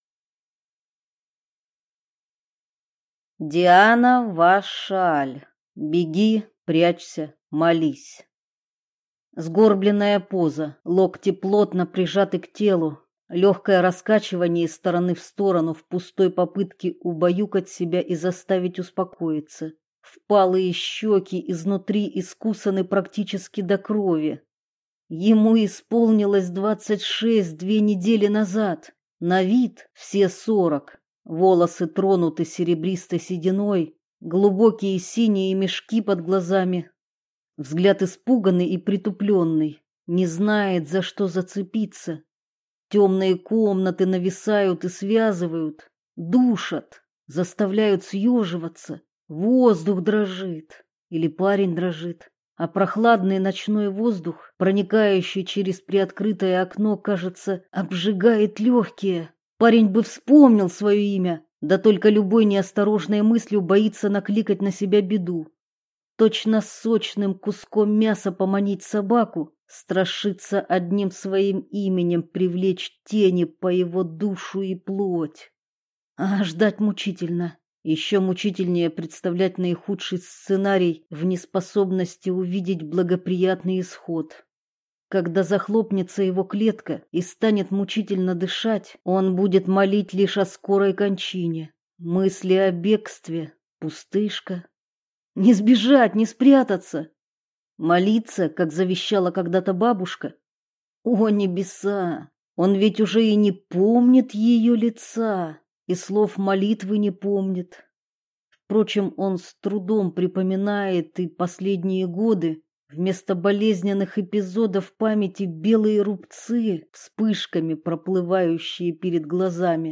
Аудиокнига Беги! Прячься! Молись!